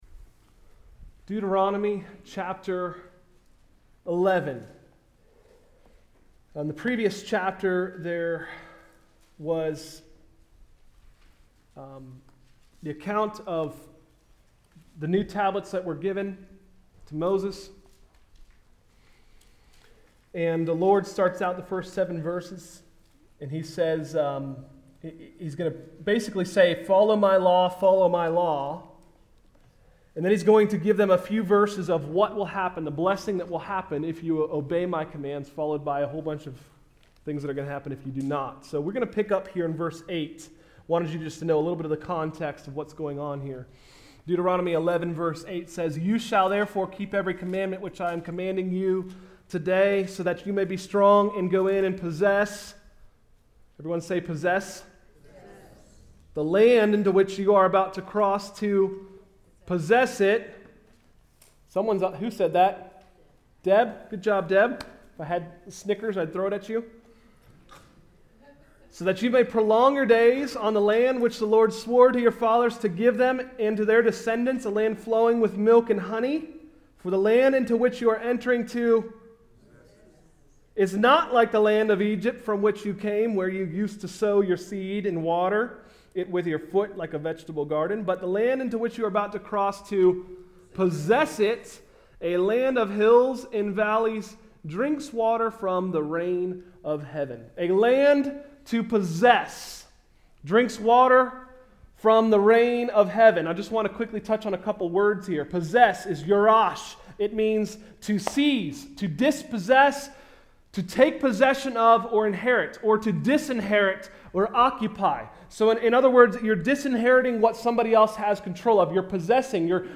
Sermons: “Rain of Heaven” – Tried Stone Christian Center